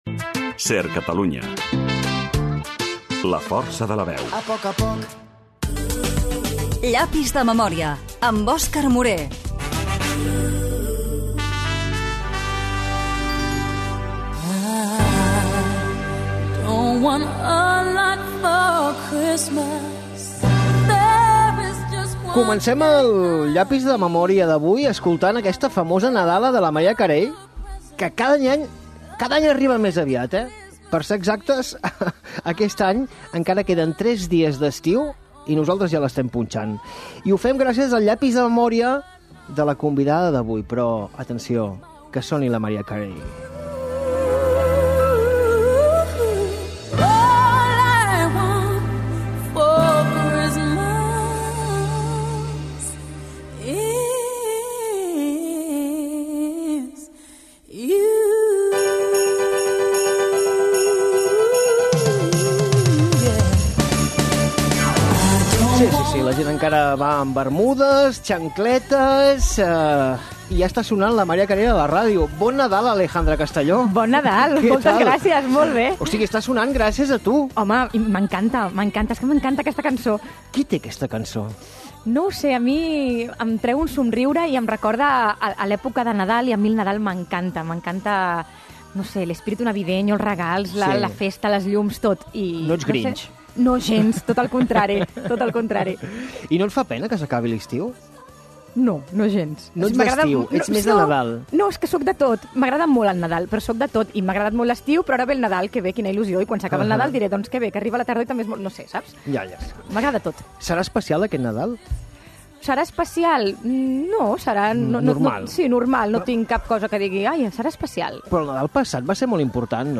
Indicatiu de la ràdio, careta, nadala, entrevista a la presentadora i influencer
Promoció dels concerts de Los 40 a les festes de La Mercè, Premios Ondas, "El balcó", Indicatiu, publicitat, indicatius del programa i la ràdio.
Entreteniment